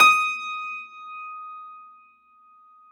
53f-pno19-D4.aif